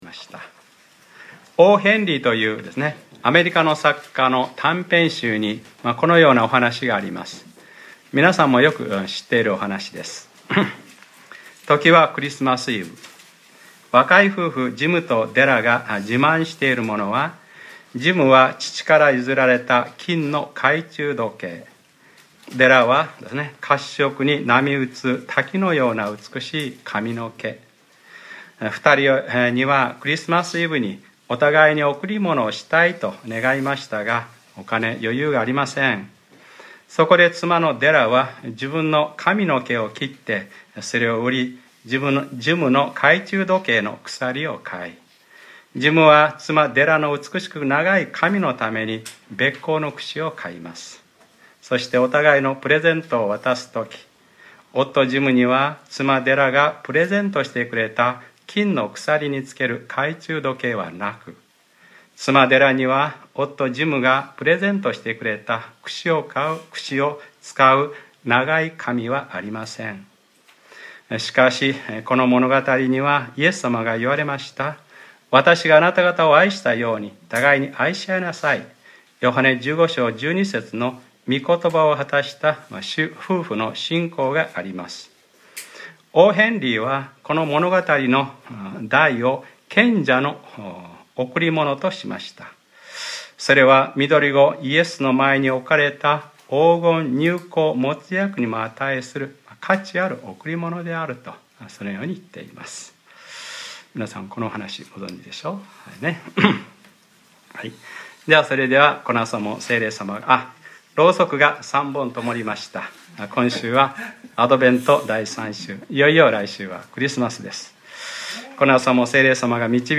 2014年12月14日）礼拝説教 『旧約聖書に於けるメシヤ(キリスト）預言』